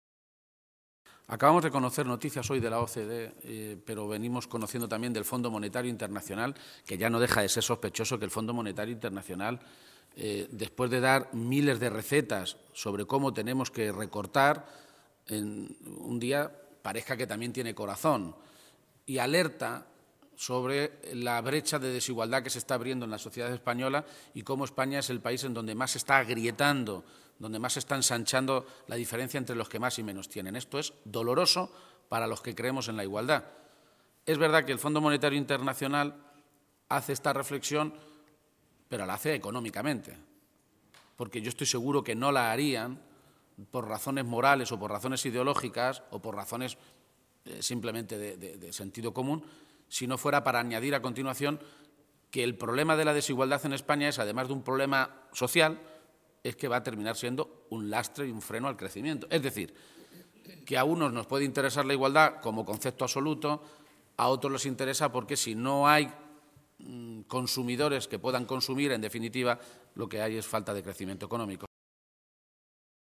García-Page se pronunciaba de esta manera esta mañana, en Toledo, a preguntas de los medios de comunicación, e insistía en que Cospedal y el consejero de Sanidad, José Ignacio Echániz, “en lo que están es en una campaña para intentar convencer a la gente de que la Sanidad funciona ahora mejor de lo que funcionaba cuando ellos llegaron al Gobierno”.
Cortes de audio de la rueda de prensa